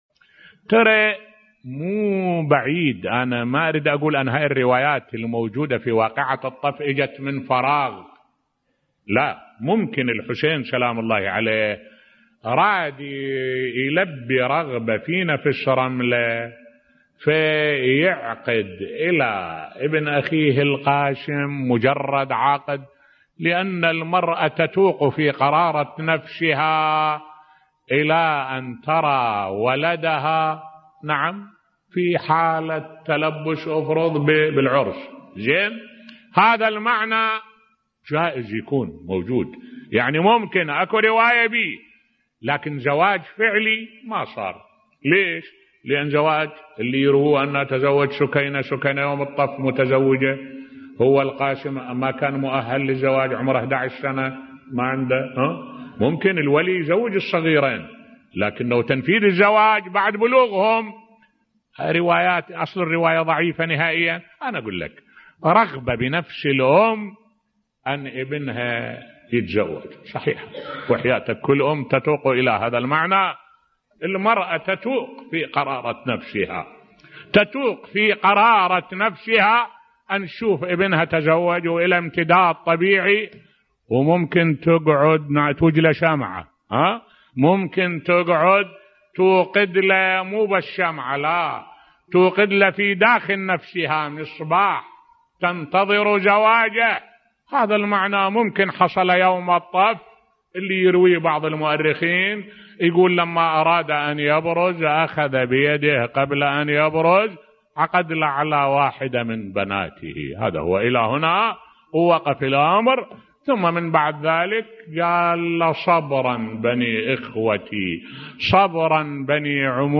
ملف صوتی إشكالية زواج القاسم في الطف بصوت الشيخ الدكتور أحمد الوائلي